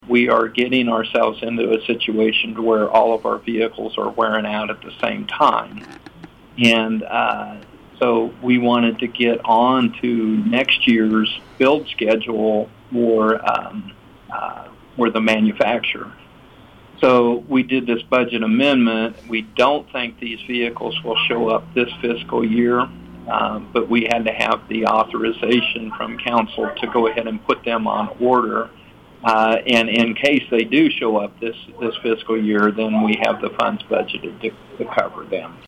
Shaw said the city has been buying used vehicles with about 40,000 miles from the Missouri State Highway Patrol for the last several years. Shaw explains why the city decided to award bids for the new vehicles.